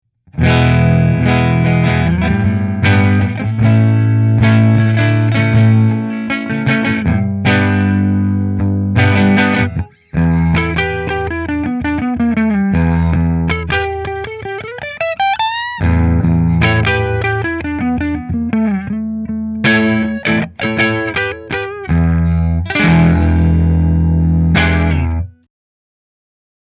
This particular model comes loaded with a pair of really hot humbuckers that will be able to push a silverface Fender into overdrive.
Aria Pro Les Paul Standard Neck Through Fender